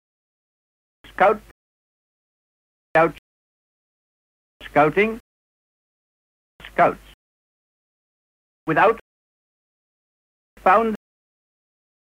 All the recordings have been subjected to lossy MP3 compression at some time during their lives.
Listen to the [au-aɔ]-like MOUTH by Robert Baden Powell (Figure 1),
ordered by rising final F1 from [au] to [aɔ]: